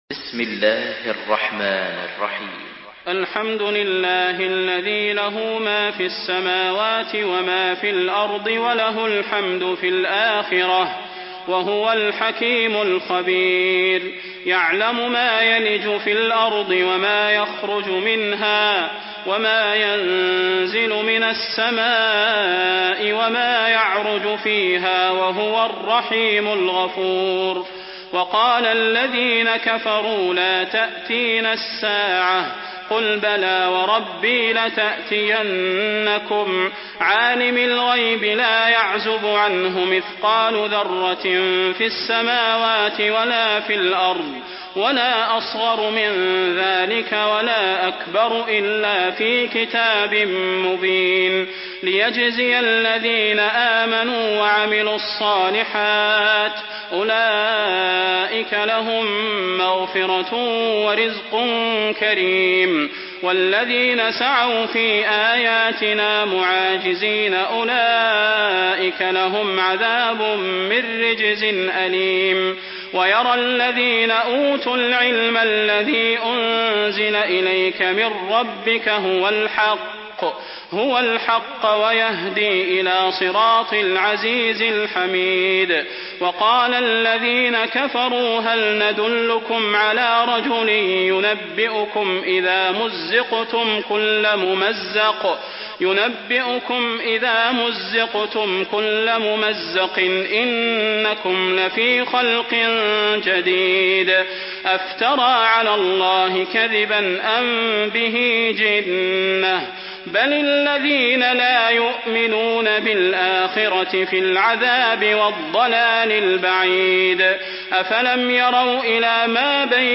Surah Saba MP3 by Salah Al Budair in Hafs An Asim narration.
Murattal